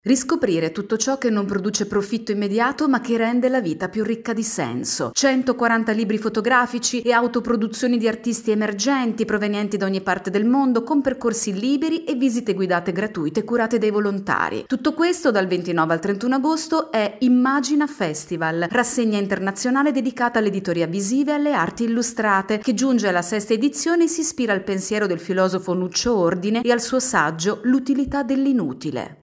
Immagina – Torna a Lucca il Festival dell’Inutile. Il servizio